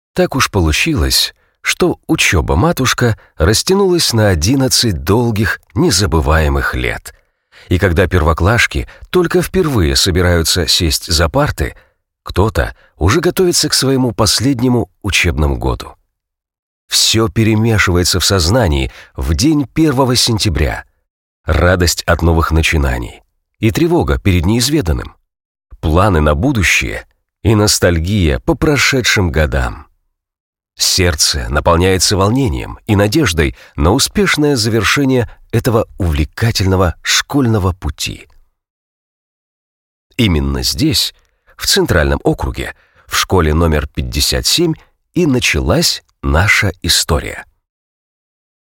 Микрофон: Neumann 87 Ai, Sennheiser MKH 416